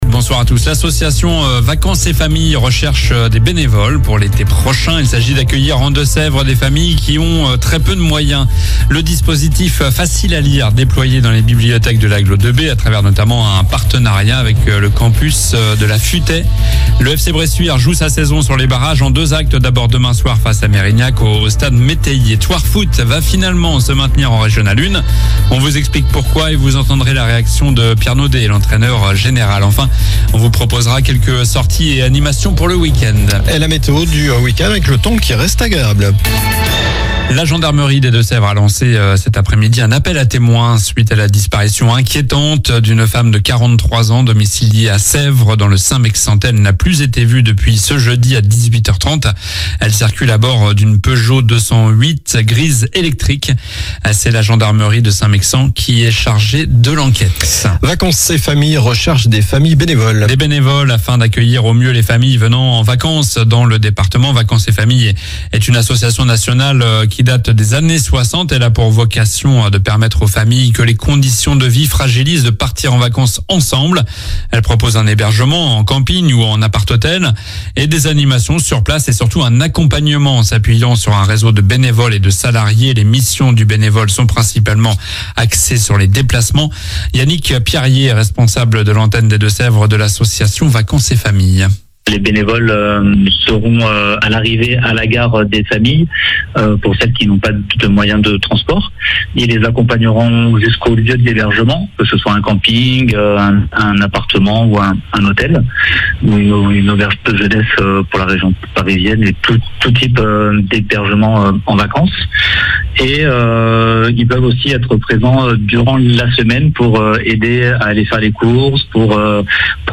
Journal du vendredi 07 juin (soir)